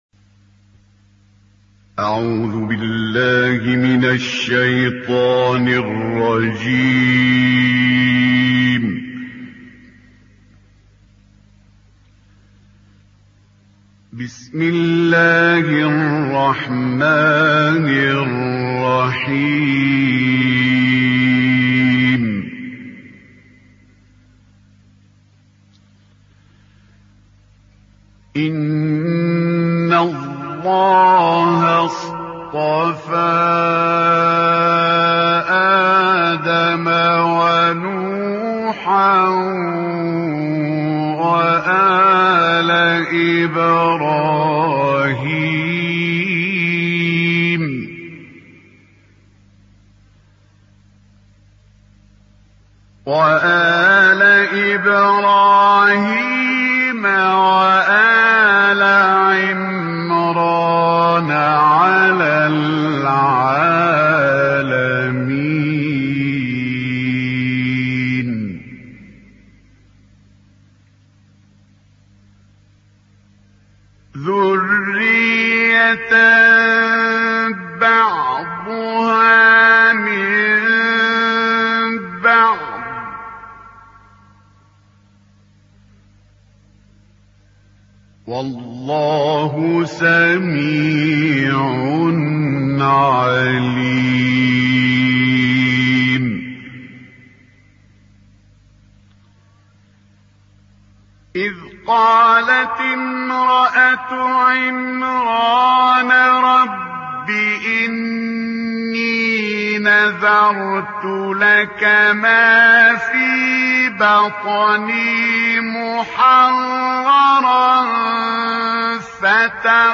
ملف صوتی ما تيسر من سورة ال عمران - 3 بصوت إبراهيم عبدالفتاح الشعشاعي